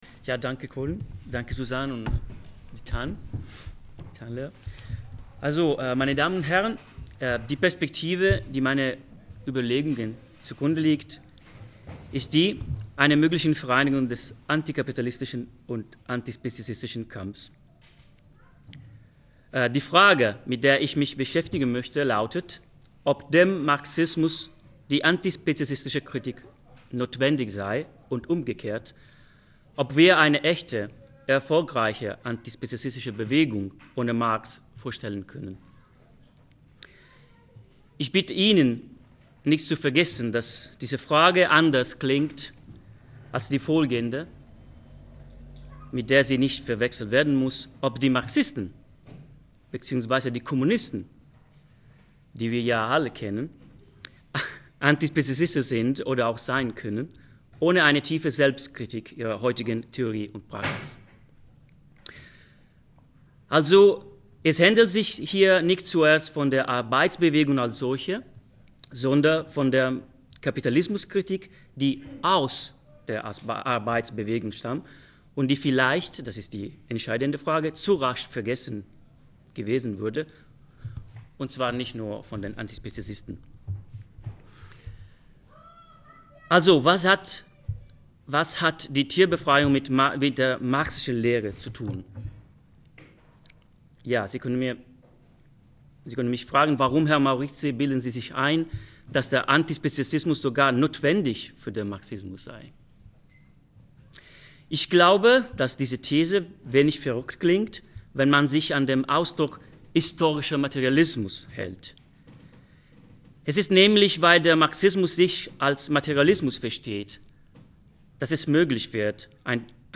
am 18. Mai 2007 im Kölibri in Hamburg anlässlich der Vorstellung des Buches.